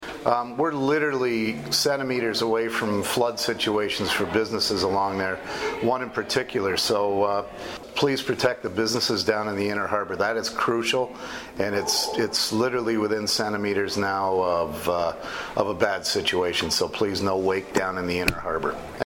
Councillor Chris Malette stressed the importance of the no wake zone enforcement.